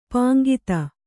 ♪ pāŋgita